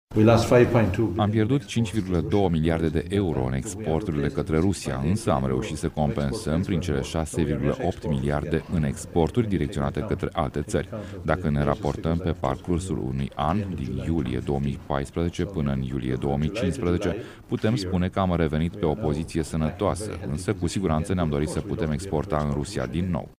În aceeaşi ordine de idei, Phil Hogan a indicat că Uniunea Europeană a reuşit să surmonteze efectele embargoului aplicat alimentelor de către Rusia: